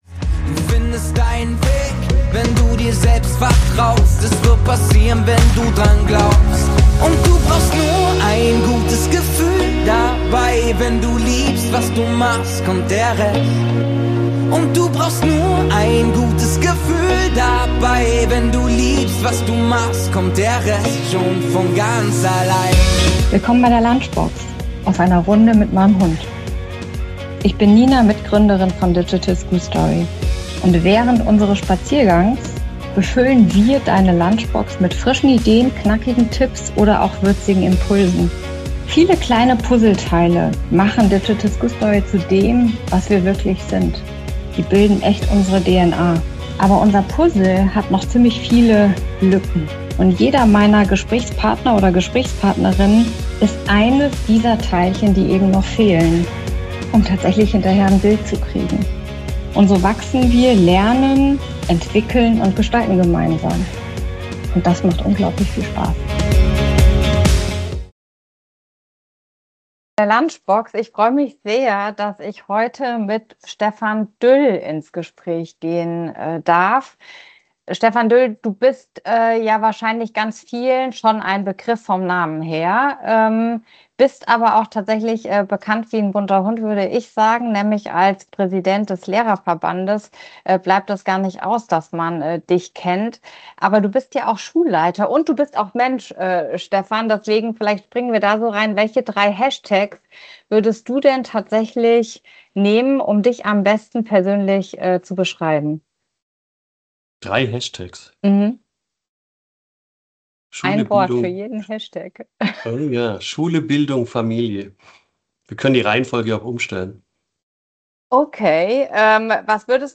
Ein Gespräch zwischen Klartext, Erfahrung und Zuversicht.